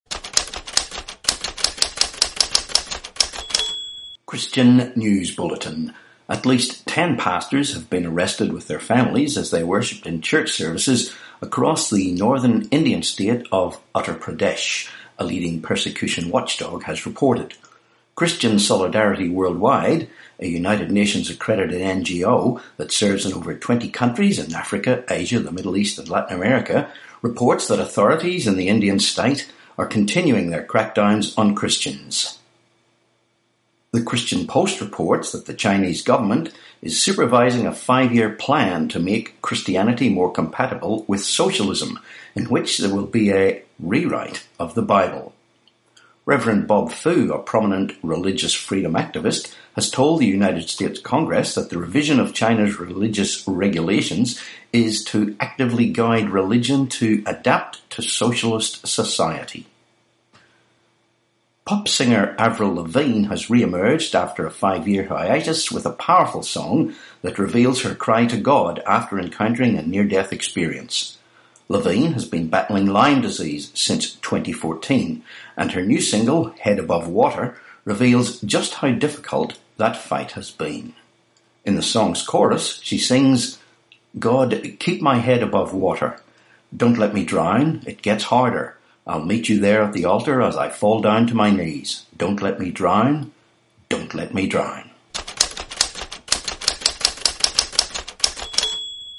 Christian, Christian News Bulletin, podcast